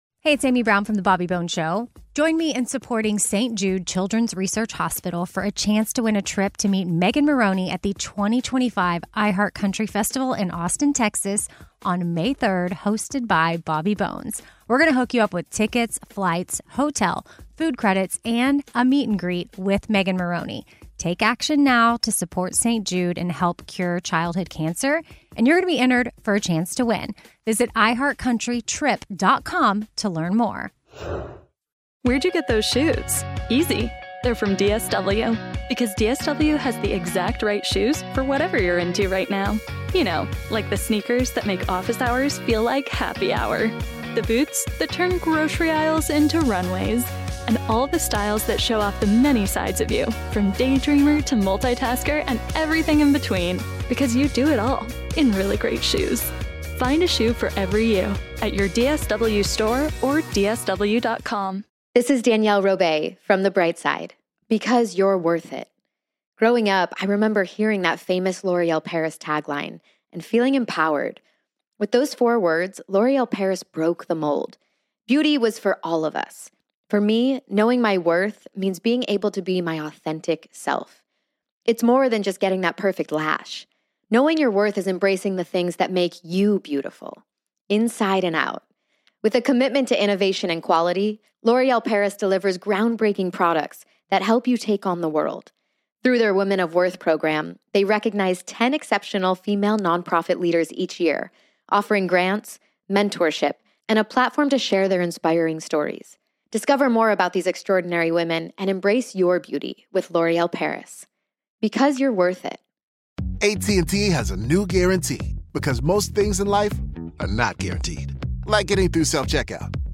Throughout the years he worked for both Hilton Hotels and Hyatt Hotels Corporation before becoming one of the founding members of the luxury hotel chain, The Ritz Carlton in 1983. Here's Horst with his story.